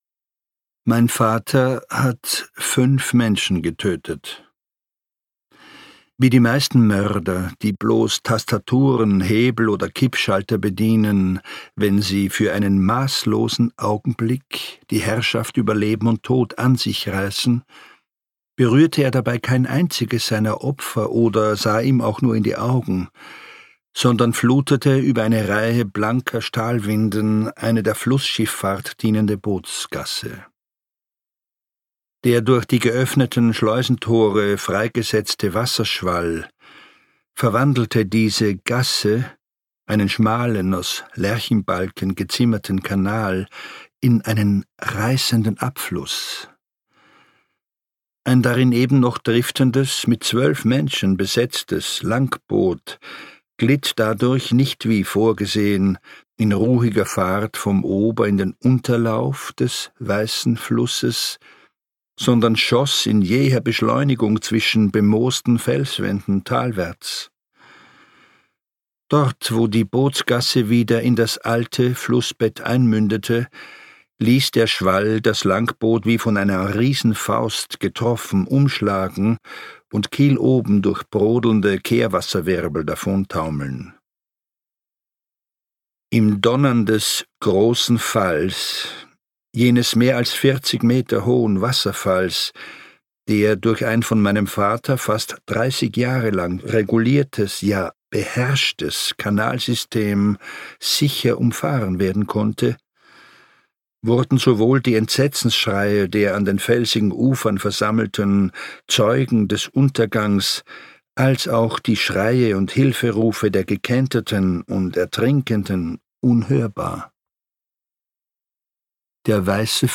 Virtuos und packend erzählt "Der Fallmeister" von einer bedrohten Welt, von menschlicher Schuld und Vergebung. Gelesen von Christoph Ransmayr.
Ungekürzte Autorenlesung